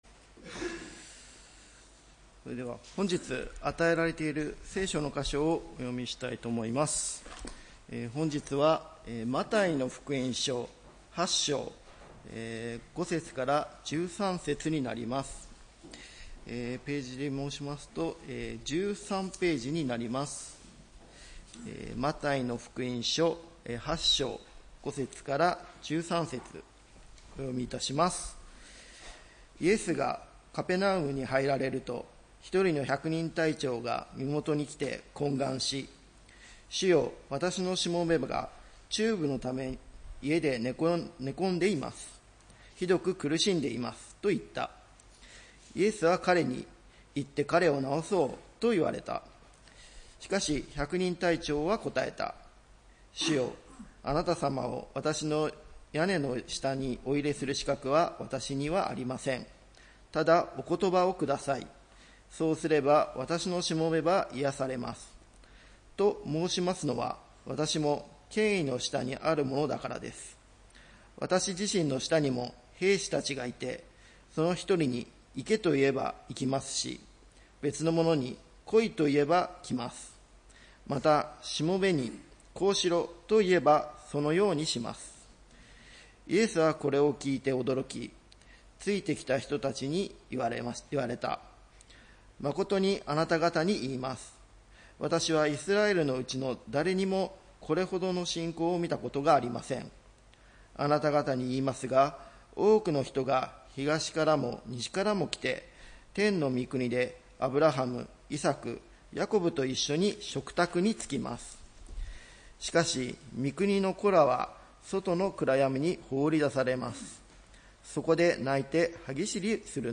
礼拝メッセージ「信じた通りに」（９月14日）